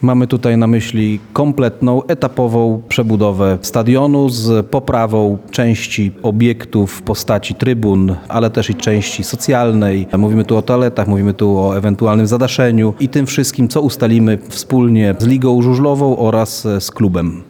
W porozumieniu z Ekstraligą i klubem sportowym koncentrujemy się obecnie na modernizacji stadionu przy Al. Zygmuntowskich i zabezpieczeniu środków na niezbędne remonty – mówi zastępca prezydenta Lublina Tomasz Fulara.